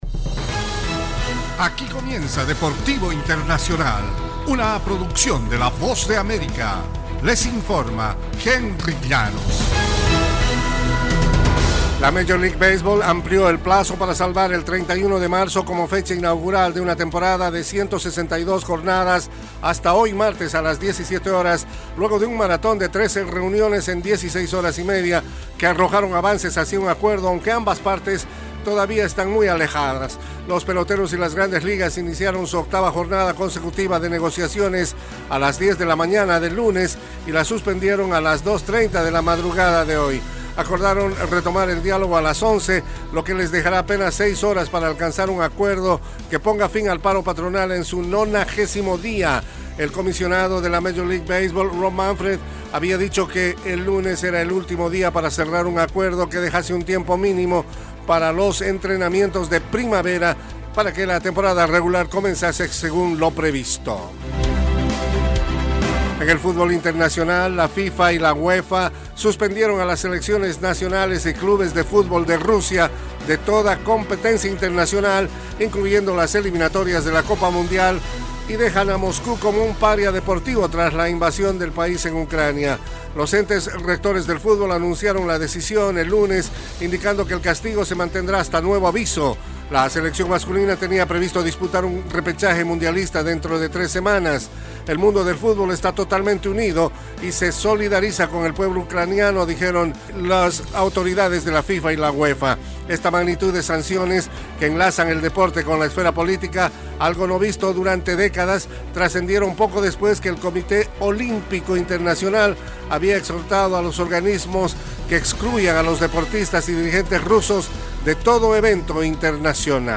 Las bnoticias deportivas llegan desde los estudios de la Voz de América